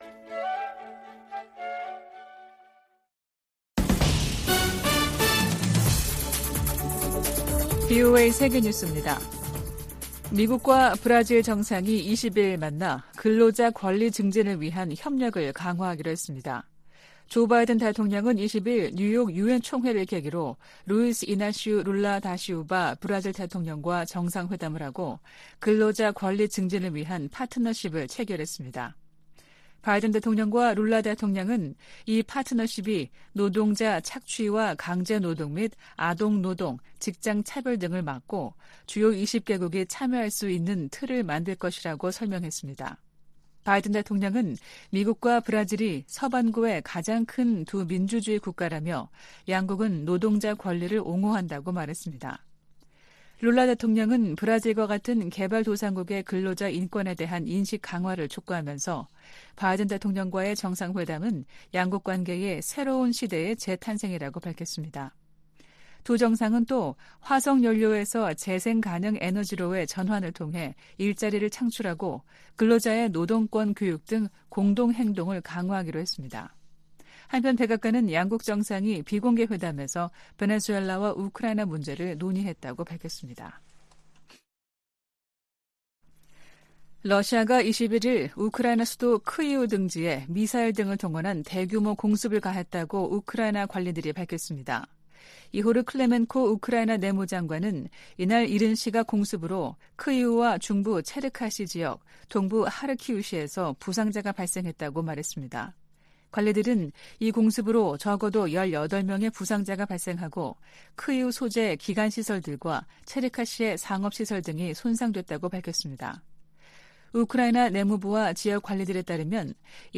VOA 한국어 아침 뉴스 프로그램 '워싱턴 뉴스 광장' 2023년 9월 22일 방송입니다. 윤석열 한국 대통령이 유엔총회 연설에서 북한의 핵과 탄도미사일 개발이 세계 평화에 대한 중대한 도전이라고 규탄했습니다.